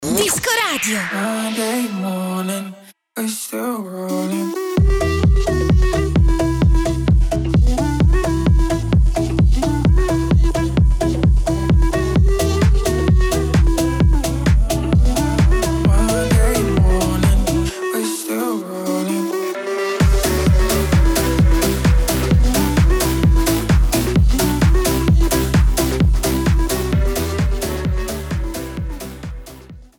dance music australiana